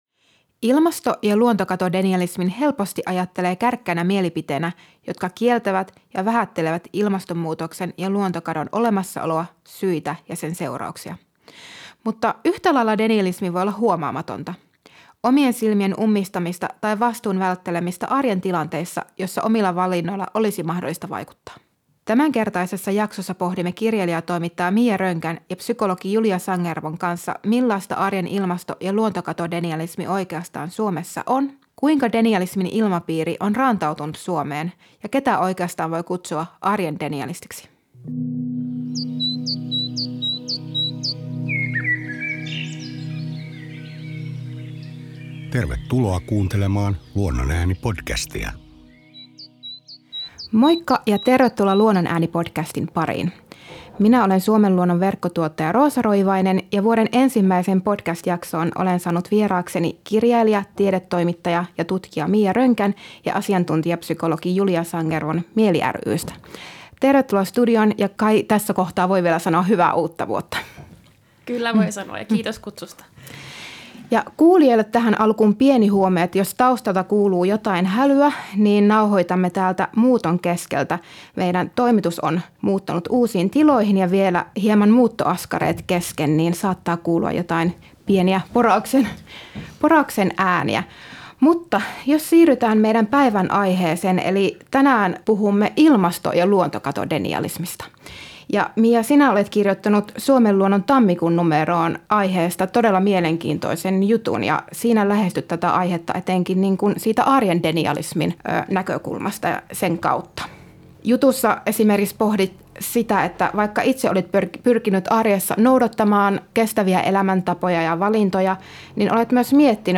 Luonnon ääni -podcastin 23. jaksossa keskustellaan muun muassa ilmastodenialismin ratkaisusta ja tulevaisuudenkuvista sekä pohditaan yksilön ja yhteiskunnan vastuuta denialismin kitkemisessä.